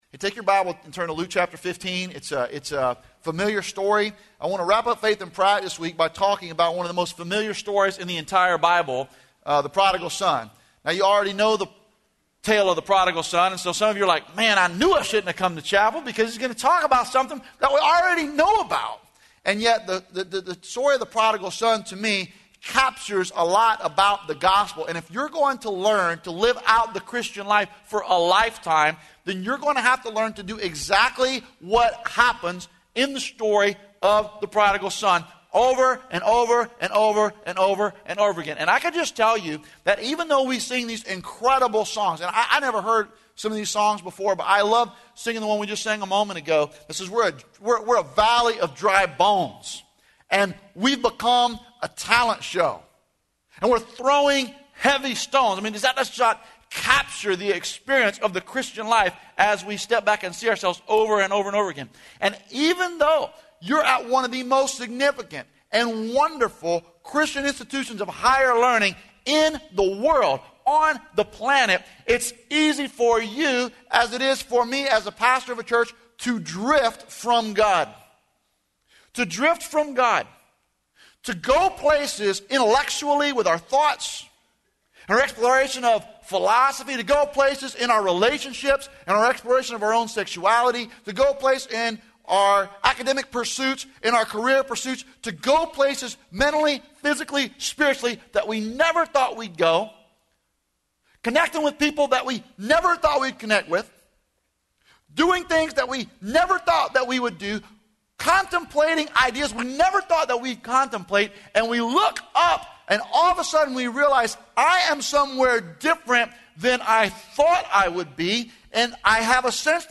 Faith in Practice Chapel
Florida Address